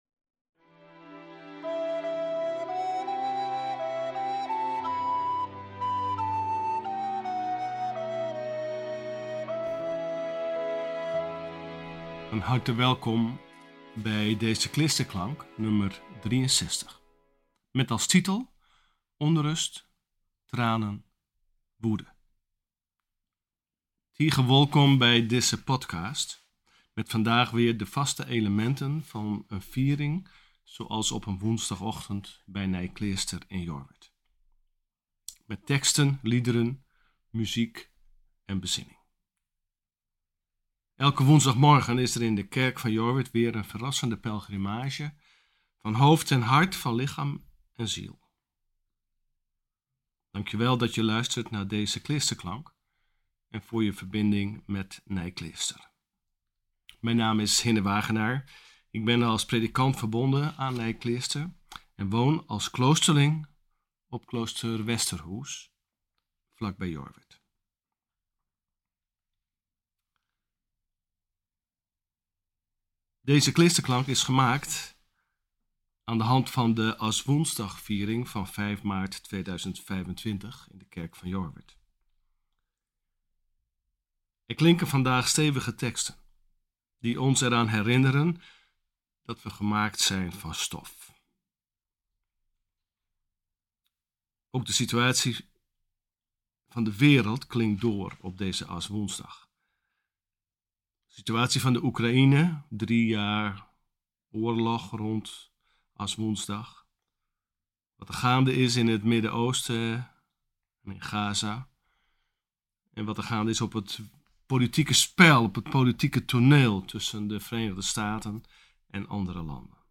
Bijbellezing